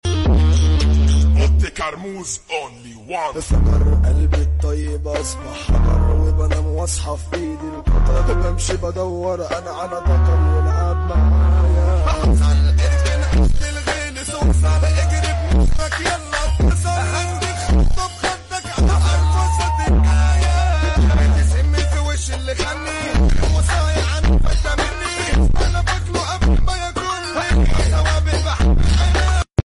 مهرجانات